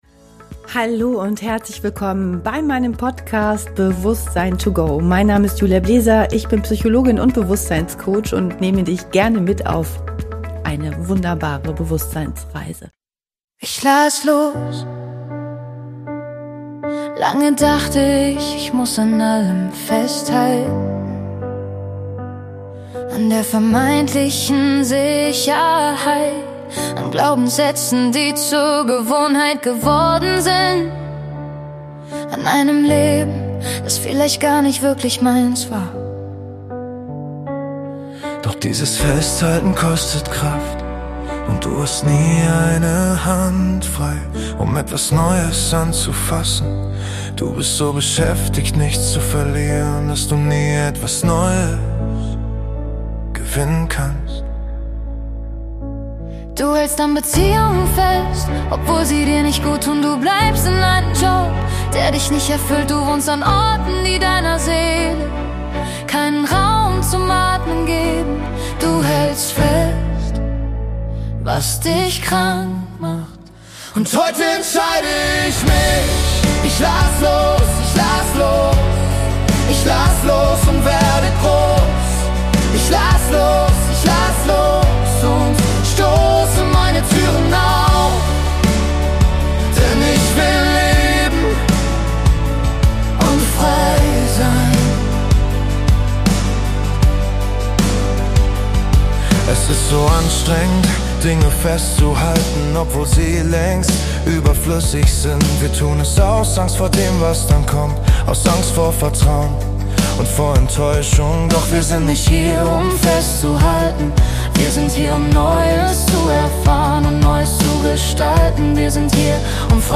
Musik für deine Seele
„Ich lass los“ ist ein kraftvoller, motivierender Song über